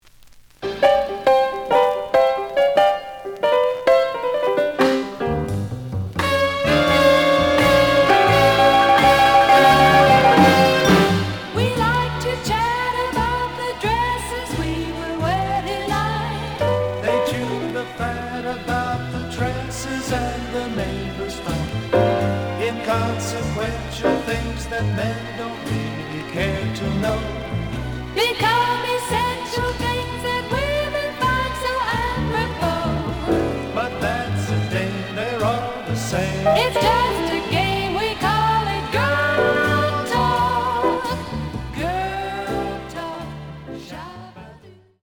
The audio sample is recorded from the actual item.
●Genre: Jazz Funk / Soul Jazz
Looks good, but slight noise on both sides.)